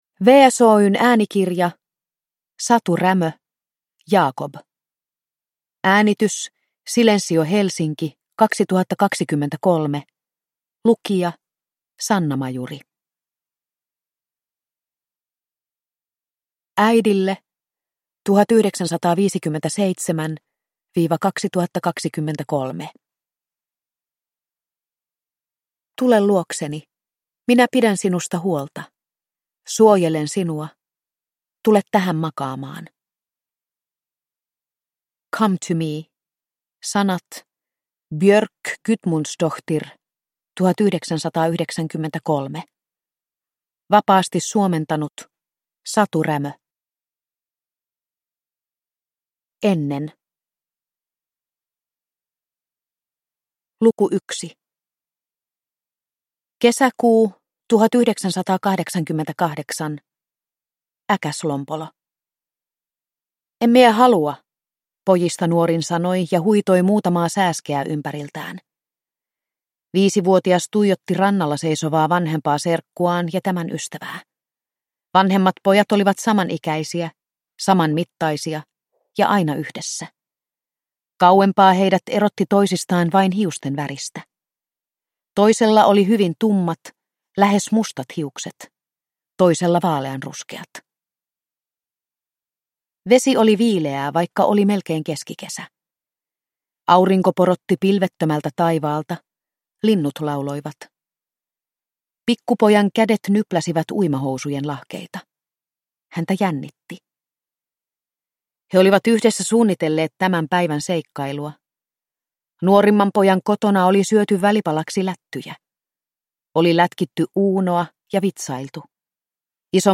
Jakob – Ljudbok – Laddas ner